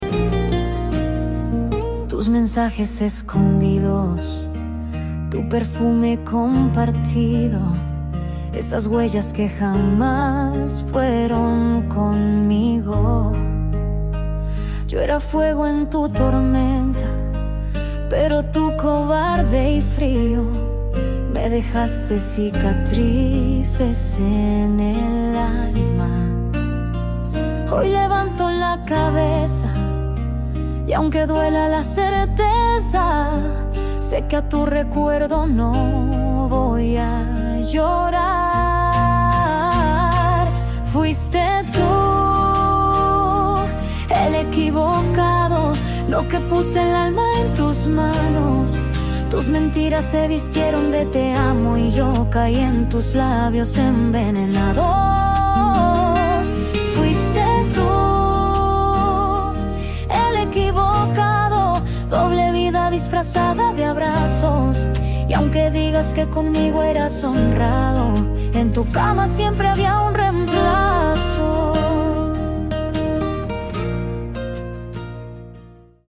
Musica regional popular Mexicana Argentina